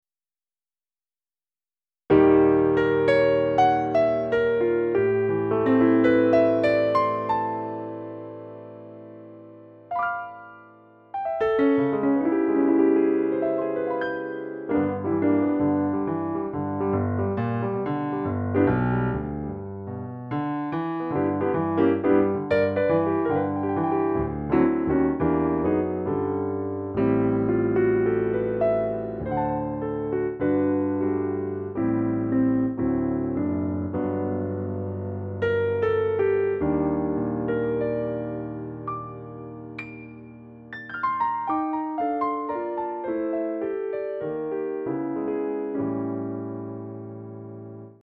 Within a week I have made these sounds, and recordings of them, using 24 bit 44.1kHz TOS link:
piano1kurz.mp3